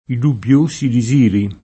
di@&re] (meno com. desiro [de@&ro] o disiro [di@&ro]) s. m. — ant. provenzalismo poet. per «desiderio»: i dubbiosi disiri [